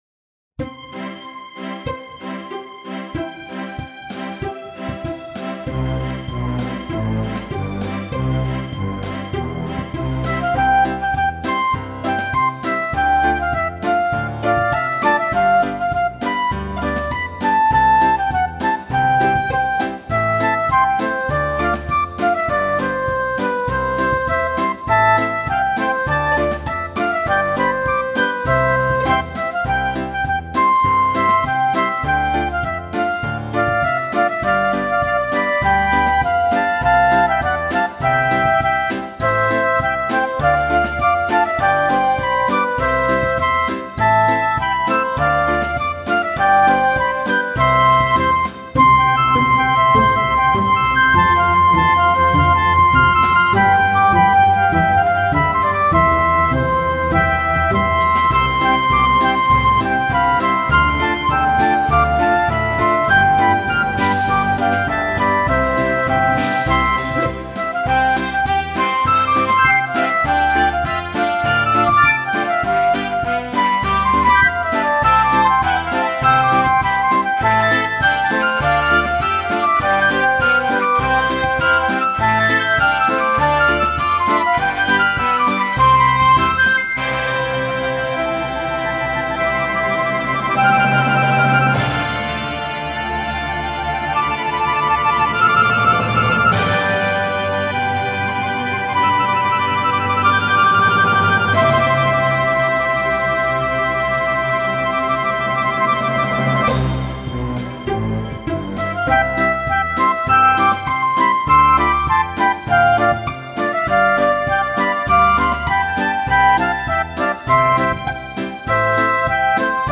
Концерт №1 для флейты mp3, 3.08 MB ЗЯ 2.